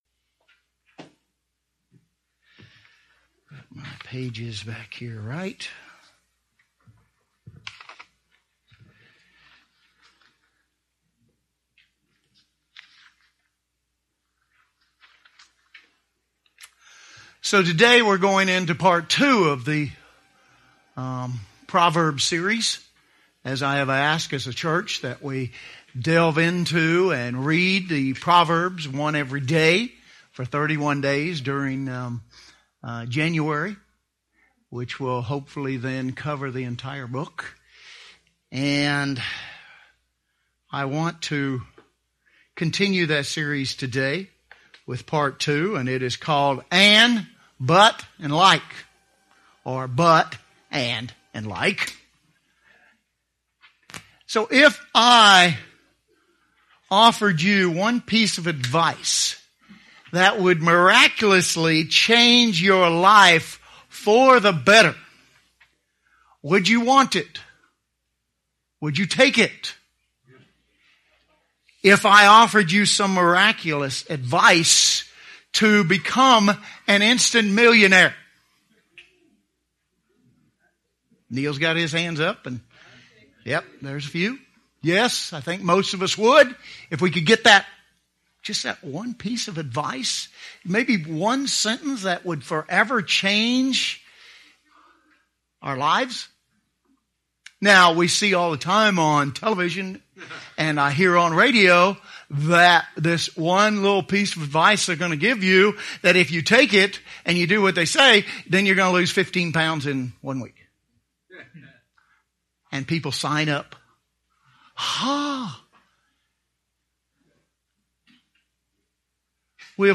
Proverbs Part 2 - "But, And, & Like" | United Church of God